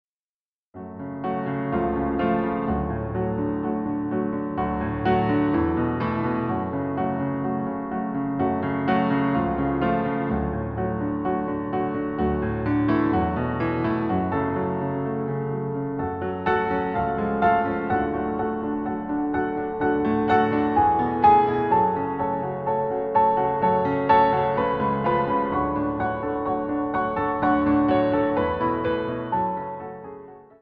• Качество: 256, Stereo
красивые
спокойные